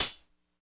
85 STE HAT-R.wav